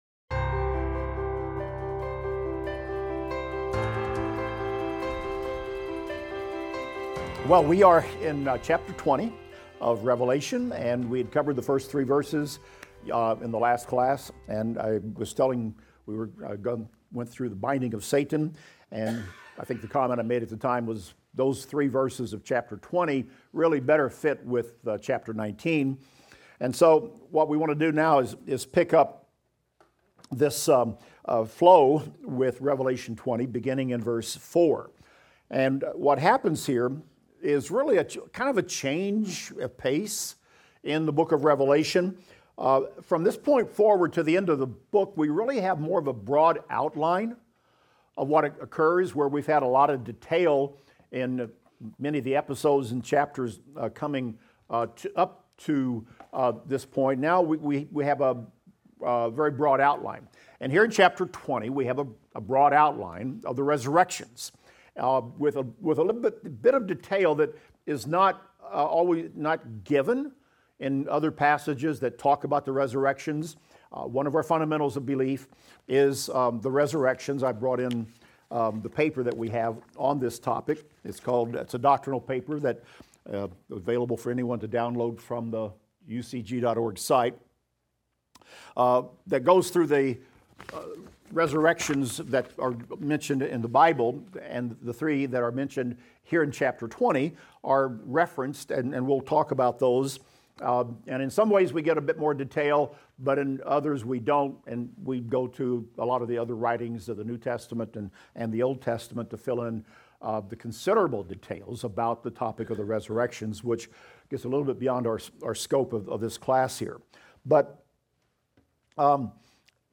Revelation - Lecture 51 - Audio.mp3